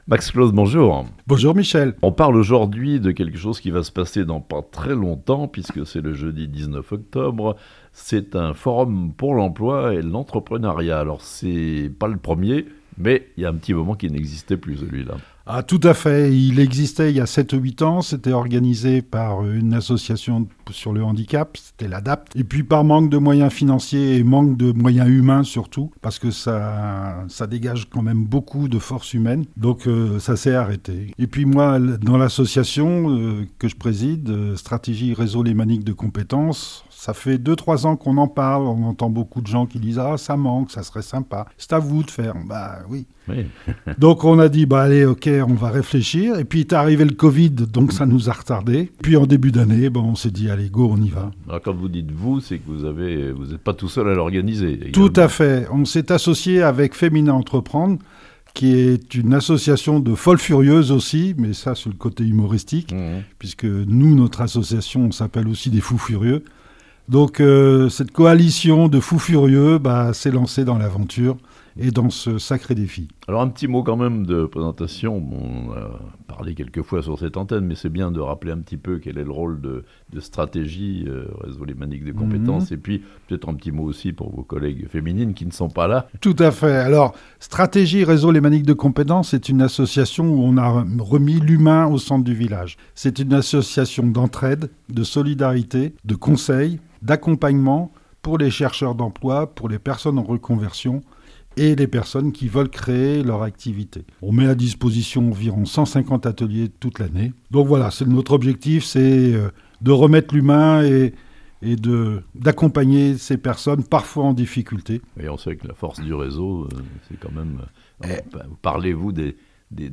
Le forum pour l'emploi fait son grand retour à Thonon ce jeudi 19 octobre (interview)